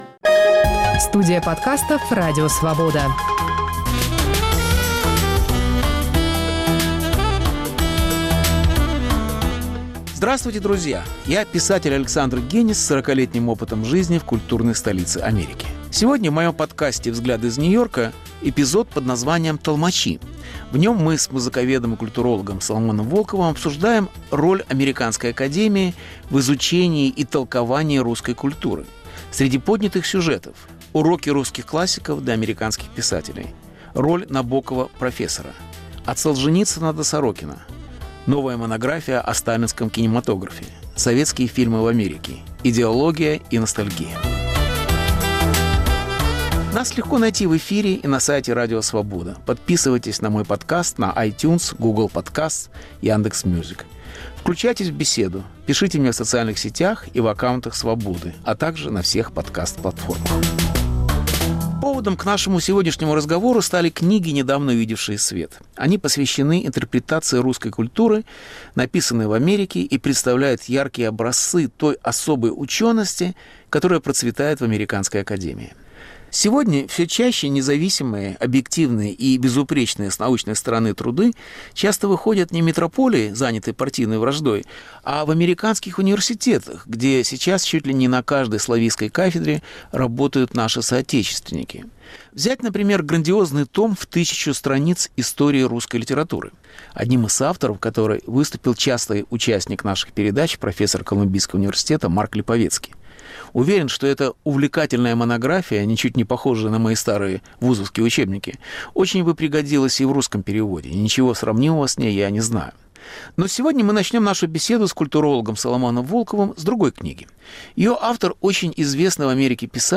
Беседа с Соломоном Волковым об интерпретаторах русской культуры в США